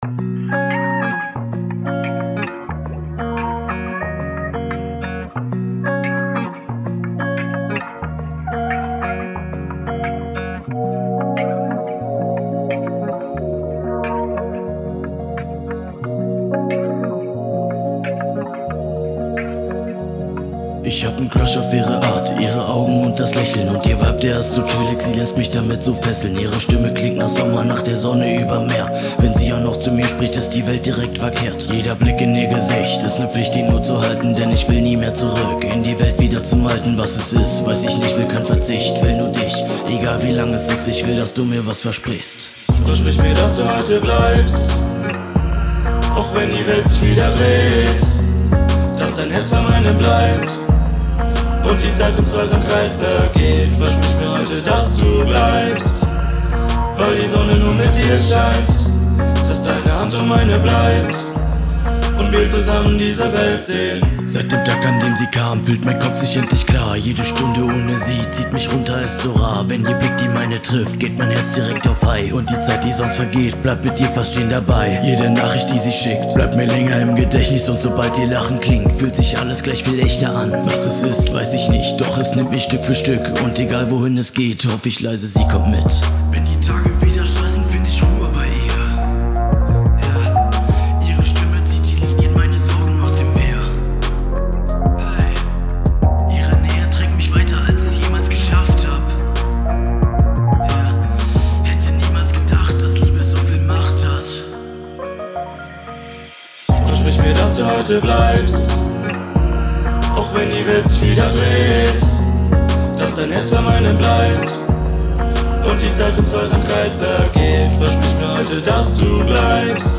Die Vocals sollen durch eine tiefere hauchige Stimme intimität herstellen. Dabei habe ich allerdings das Problem, dass der Song in den Strophen zwischen 80 - 120 Hz in meinem Auto mit einer nachgerüsteten Soundanlage inklusive Subwoofer im Kofferraum etwas wummig klingt.
Den Master habe ich angepasst an den Ozone Balance Control mit dem Preset HipHop abgestimmt. In der zweiten Strophe habe ich die Vocals nur in mehreren takes hinbekommen, da ich eigentlich kein Sänger bin.